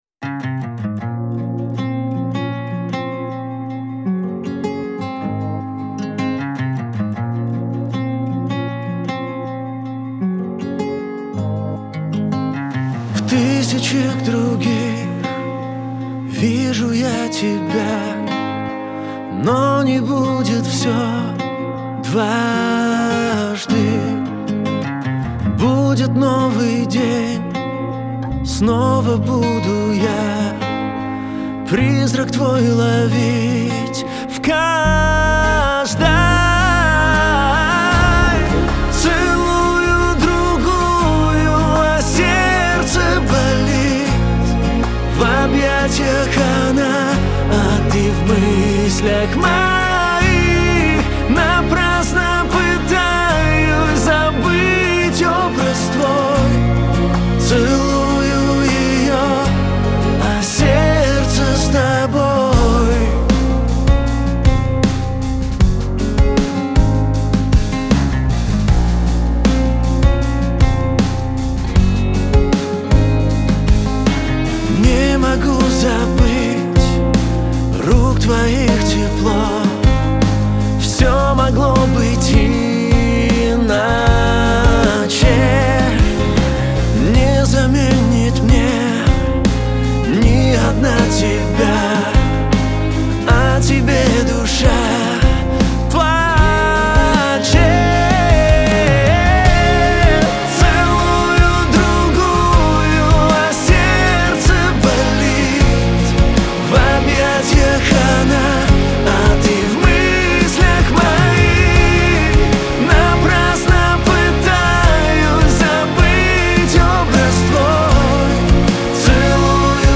Лирика , диско , pop
эстрада